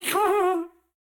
Minecraft Version Minecraft Version snapshot Latest Release | Latest Snapshot snapshot / assets / minecraft / sounds / mob / happy_ghast / ambient4.ogg Compare With Compare With Latest Release | Latest Snapshot